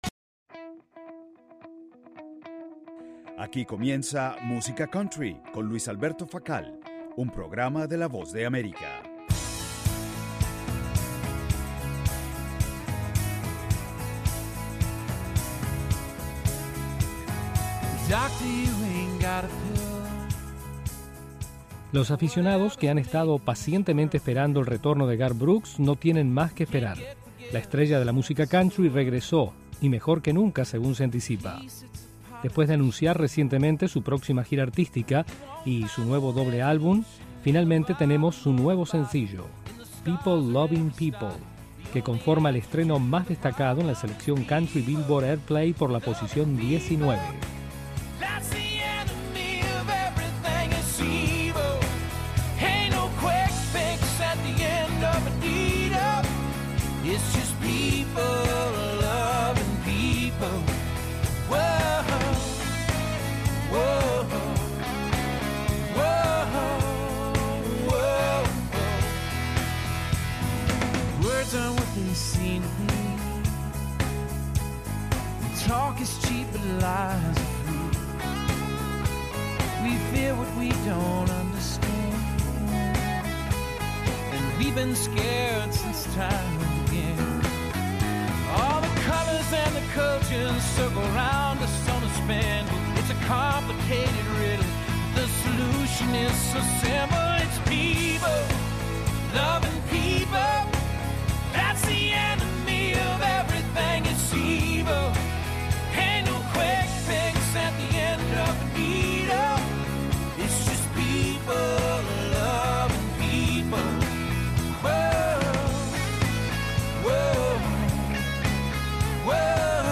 el programa musical